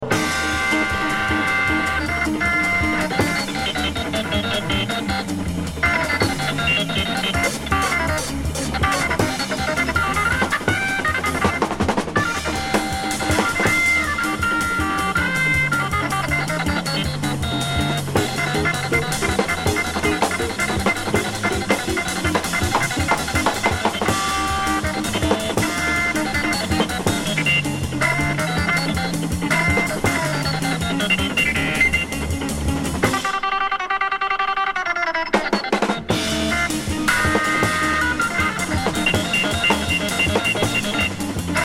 Enregistrement dans les 7 ties en live au halfnote à NY